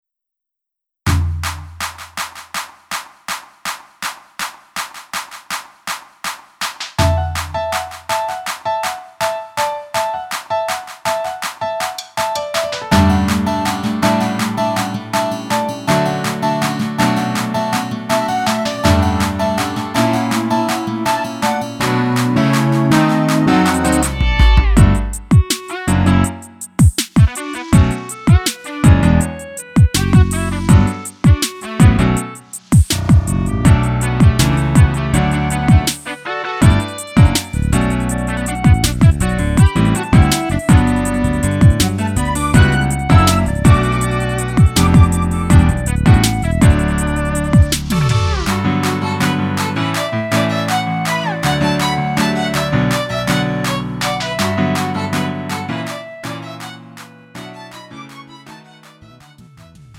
음정 원키 3:42
장르 가요 구분